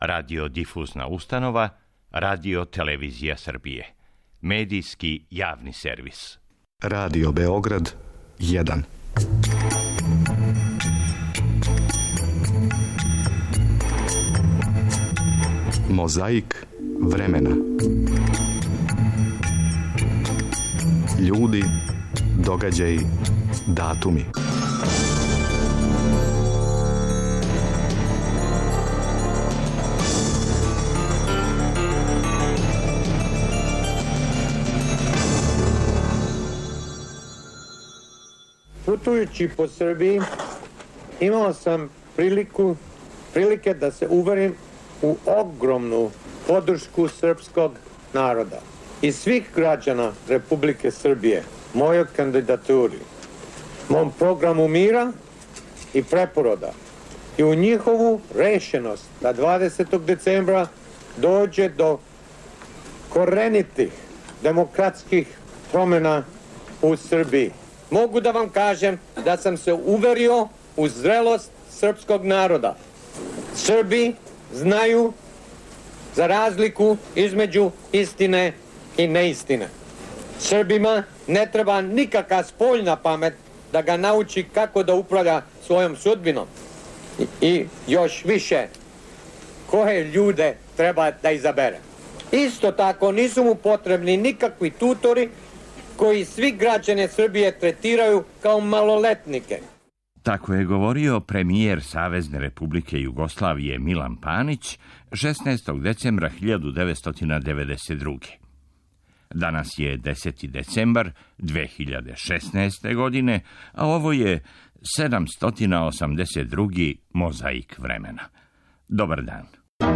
За нас је занимљив говор највећег сина наших народа и народности који је одржао 15. децембра 1948. године - друг Тито се обратио присутнима на заједничком конгресу СКОЈ-а и Народне омладине.